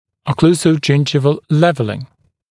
[əˌkluːsə(u)ˈdʒɪndʒɪvl ‘levəlɪŋ] [эˌклу:со(у)ˈджиндживл ‘лэвэлин] окклюзионнодесневое выравнивание